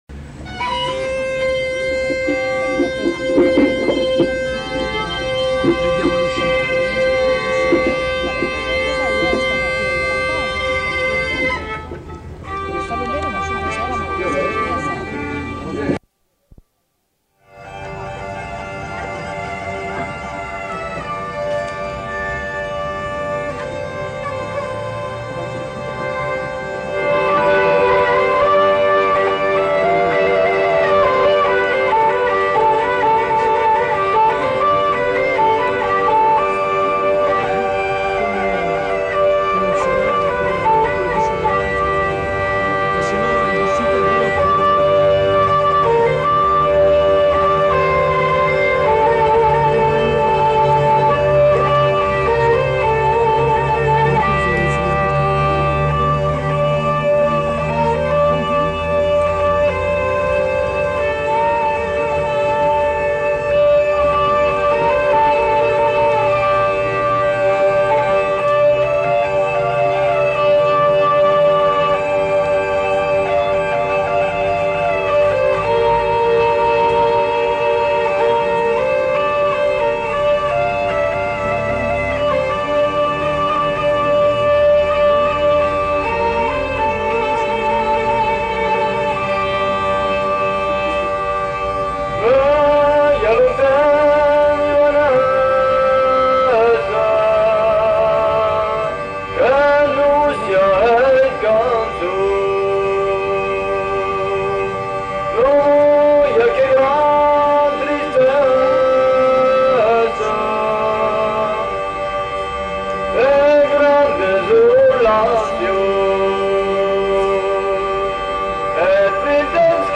Aire culturelle : Pays d'Oc
Lieu : Pinerolo
Genre : chanson-musique
Effectif : 1
Type de voix : voix d'homme
Production du son : chanté
Instrument de musique : vielle à roue